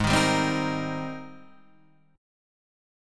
G# Major 9th